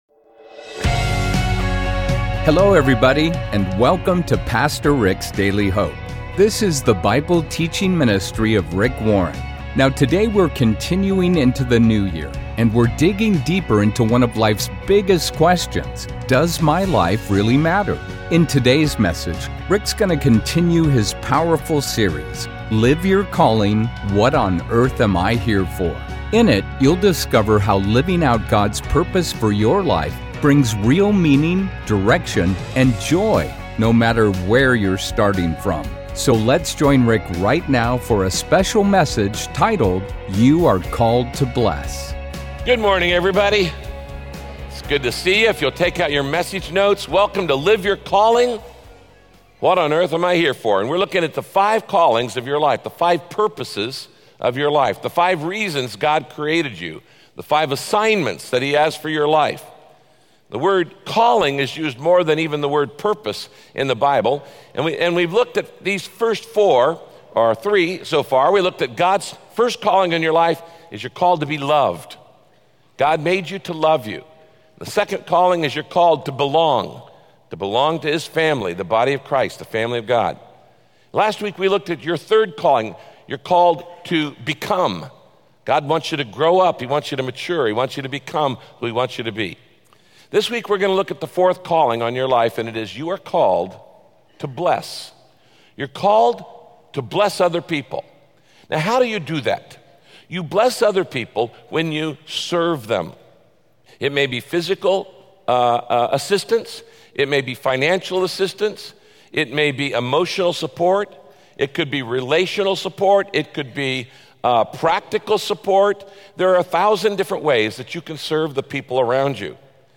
There's almost nothing that God won't do for the person who really wants to help others. In this broadcast, Pastor Rick shares God's promises of what he will do for the person who is committed to blessing and helping other people.